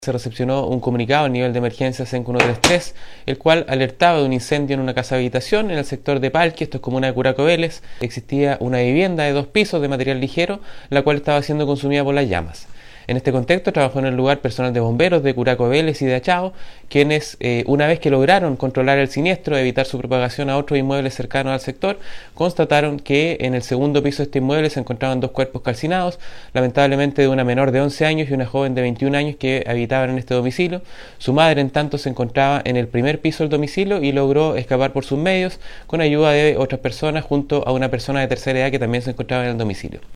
MUERTOS-INCENDIO-CDV-cu-MAYOR-CARABINEROS.mp3